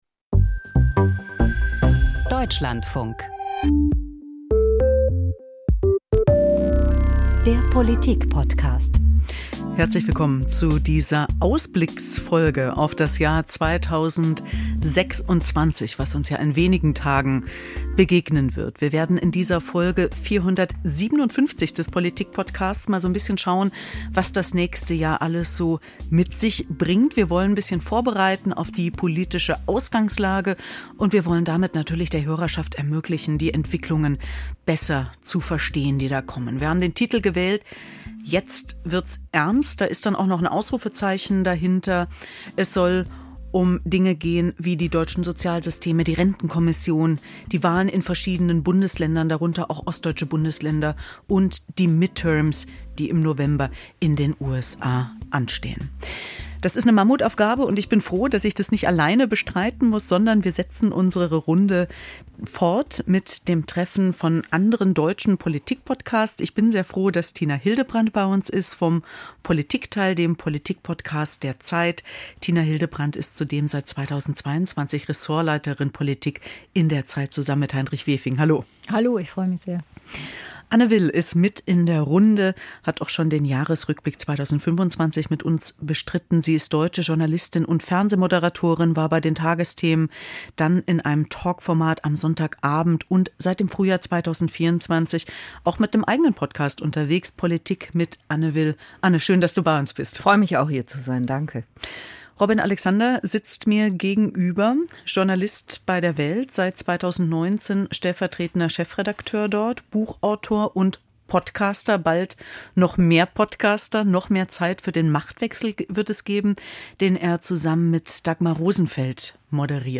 Die Hosts von "Machtwechsel", "Das Politikteil", "Lage der Nation" und "Politik mit Anne Will" treffen sich im Politikpodcast zum Jahresausblick 2026.